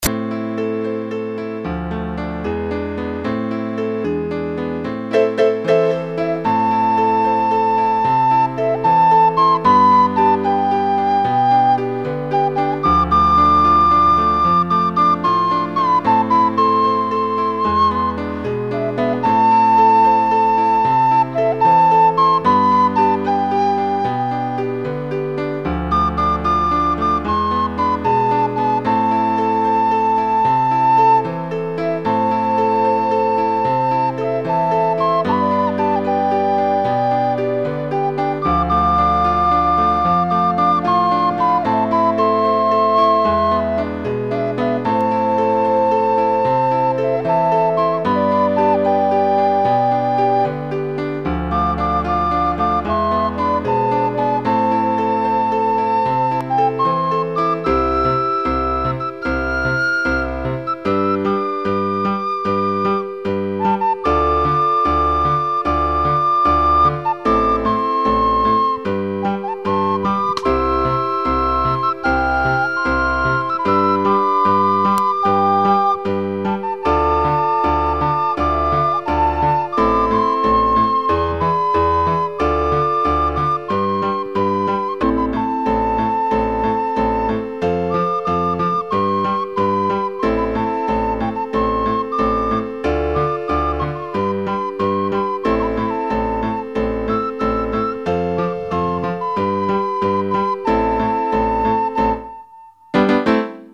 וגם האיכות טובה מאוד. אהבתי.
מנגינה מעולה רגועה ואיכותית.
רק הפריע לי בסוף השלושה בומים של הפסנתר..לדעתי עדיף לסיים את זה בצליל אחד ארוך..
המעבר של הפסנתר באמצע לקצב אחר הוציא אותי קצת מהריגוש
ההקלטה טובה, שומר על קצב, לחן חביב ביותר.
איזה רוגע...:P